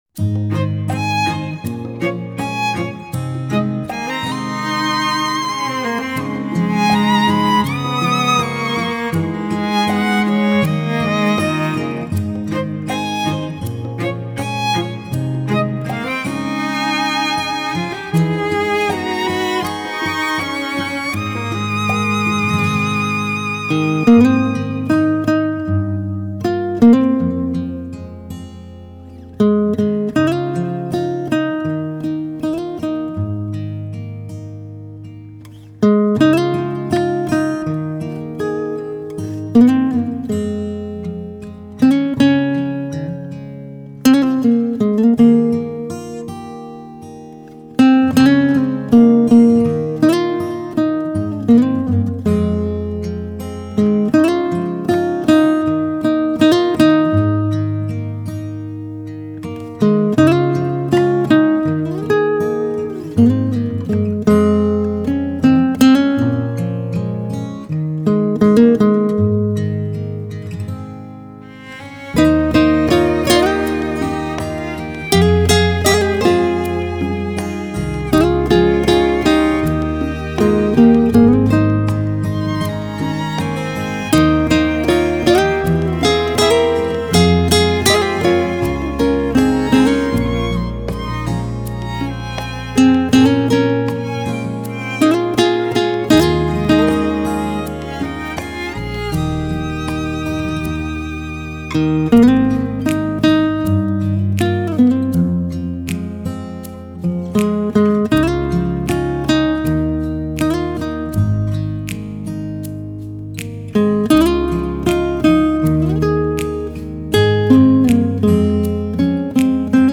Nhạc Thư Giãn